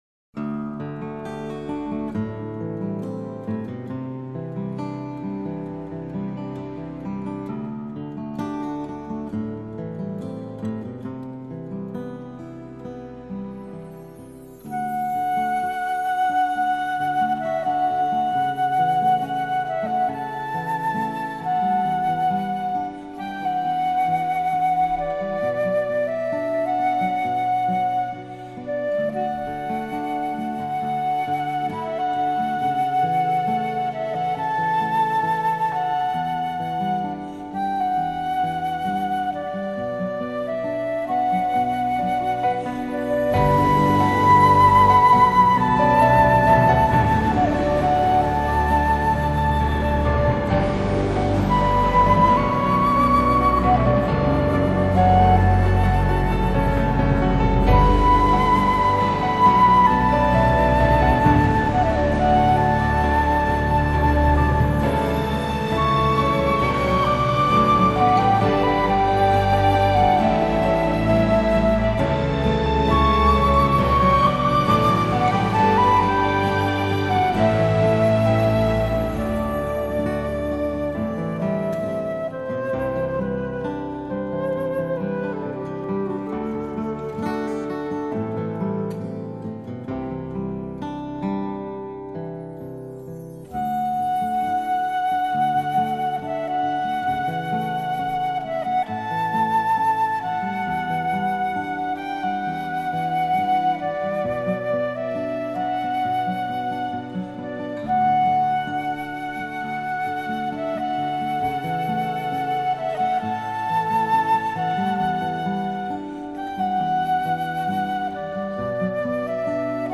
音质不好,不太值得买.